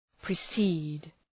{prı’si:d}